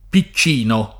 pi©©&no] agg.